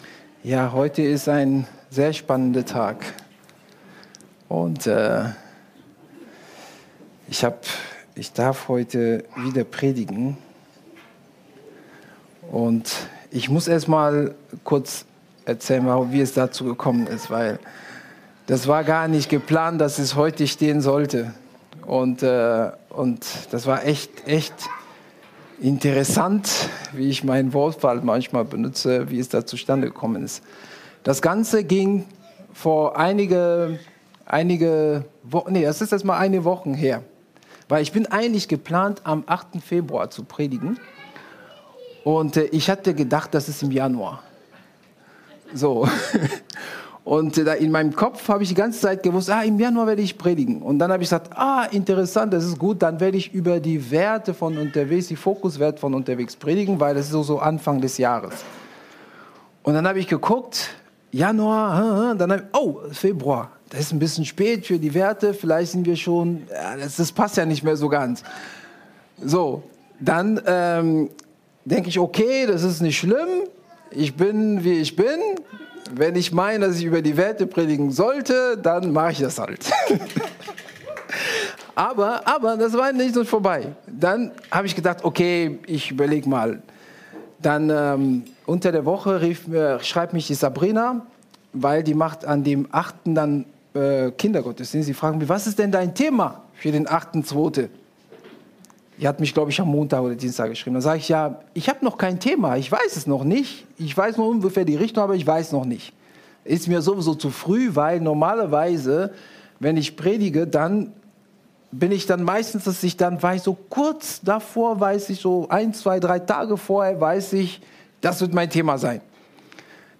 Seine Gnade entdecken – Vergebung, Heilung und Freiheit für dein Herz ~ Predigt-Podcast von unterwegs FeG Mönchengladbach Podcast